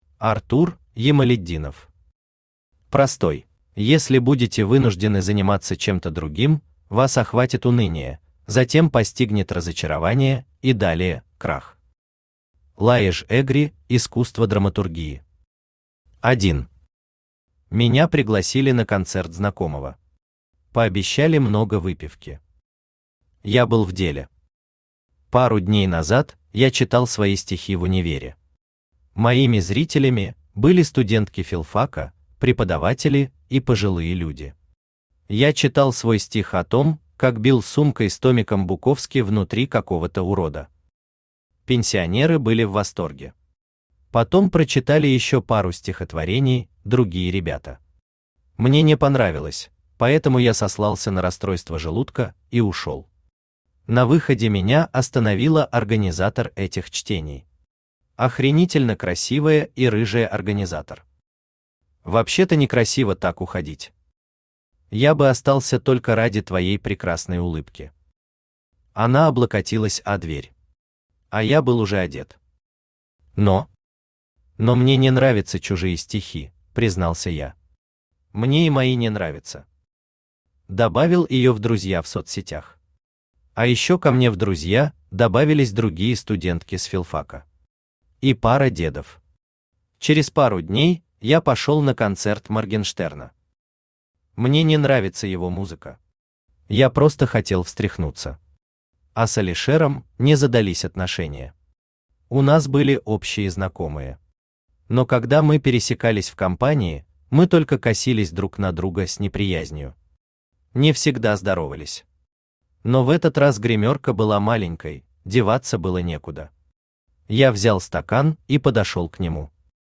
Aудиокнига Простой Автор Артур Рустэмович Ямалетдинов Читает аудиокнигу Авточтец ЛитРес.